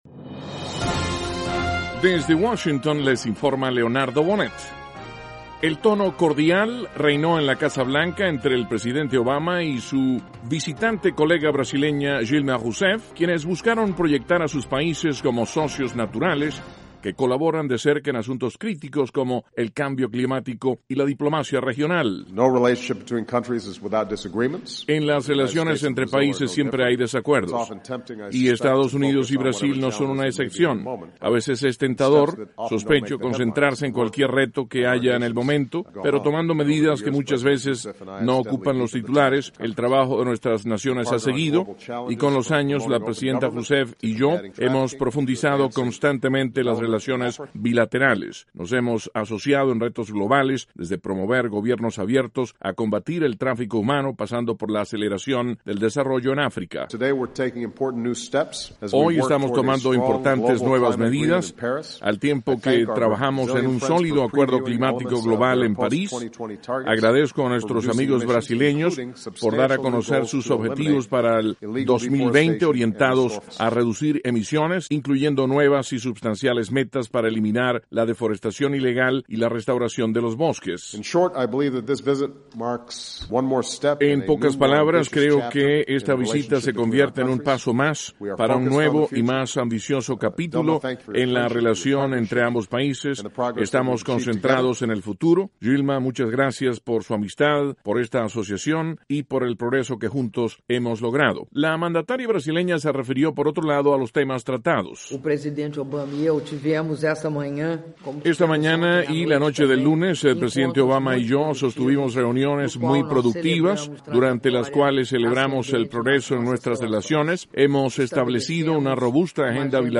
.- El presidente Obama recibe en la Casa Blanca a la primera mandataria de Brasil, Dilma Rouseff. (Sonidos – 3 Obama / 2 Rouseff).